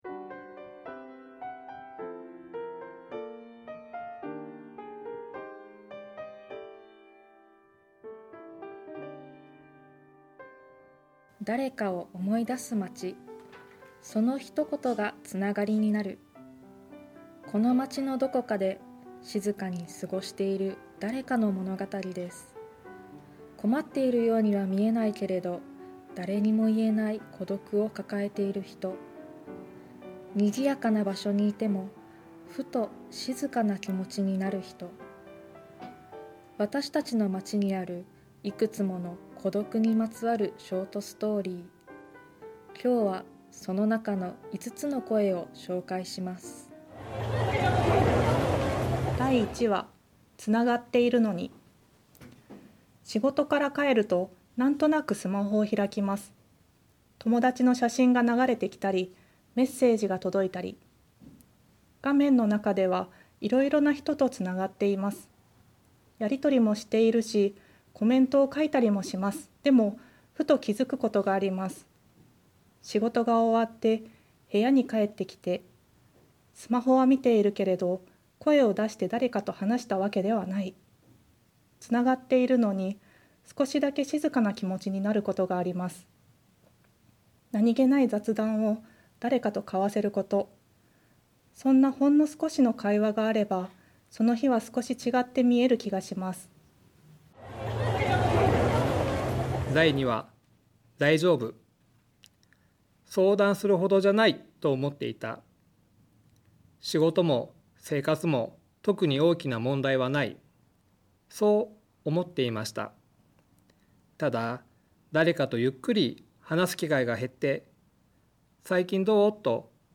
目次　（朗読音声は